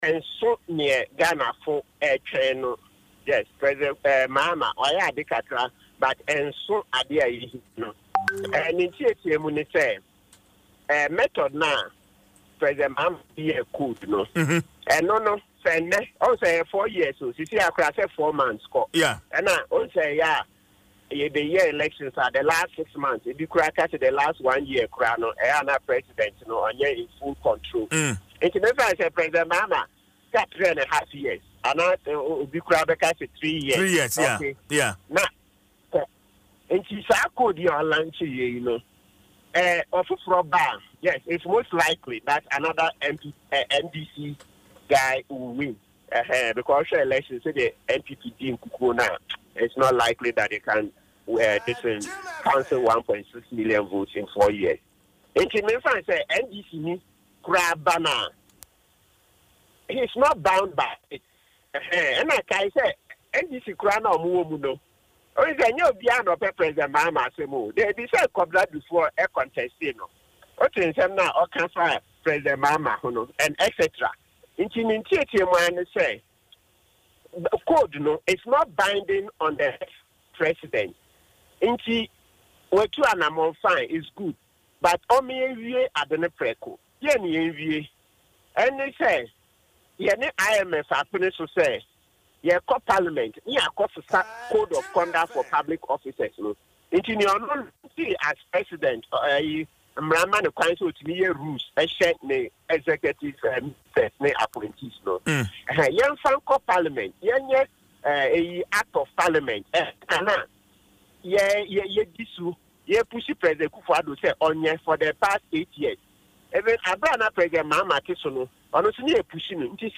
Speaking in an interview on Adom FM’s Dwaso Nsem